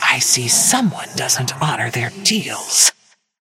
Haze voice line - I see someone doesn't honor their deals.